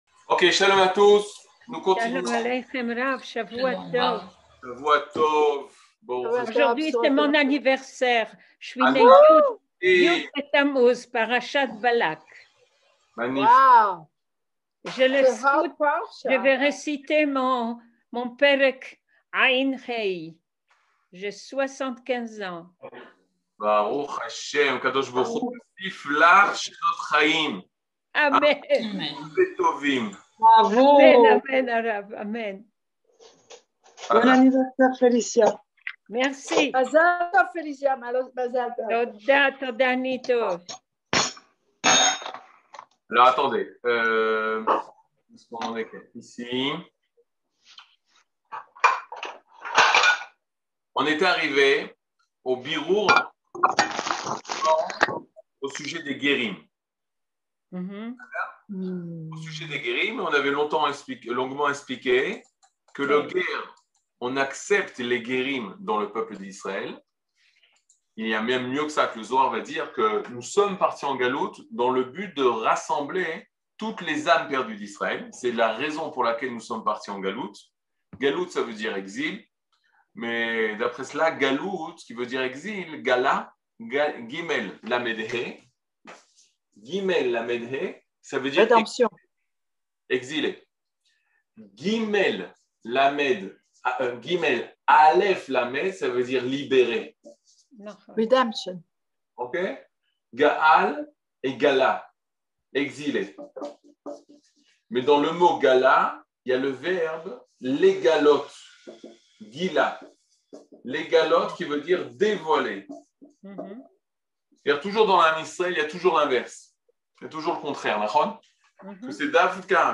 Catégorie Le livre du Kuzari partie 24 00:57:15 Le livre du Kuzari partie 24 cours du 16 mai 2022 57MIN Télécharger AUDIO MP3 (52.4 Mo) Télécharger VIDEO MP4 (134.21 Mo) TAGS : Mini-cours Voir aussi ?